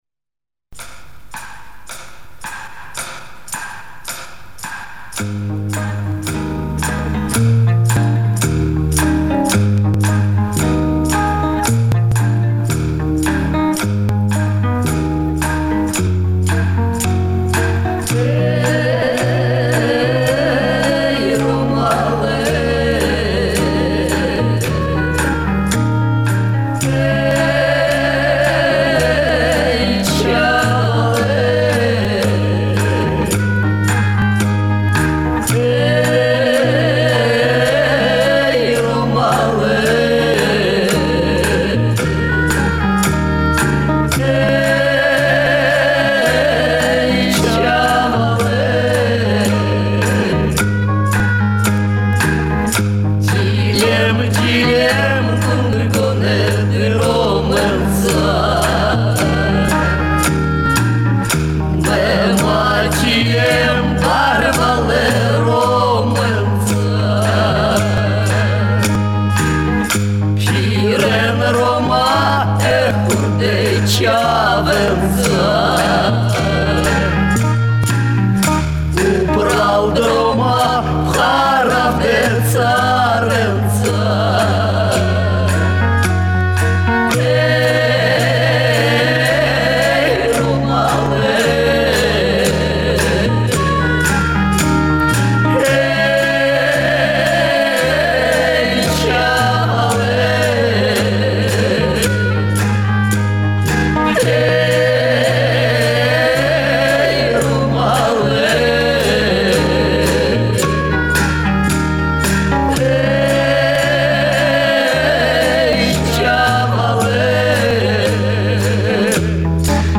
песня югославских цыган (музыка и слова народные)